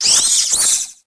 Cri de Badabouin dans Pokémon HOME.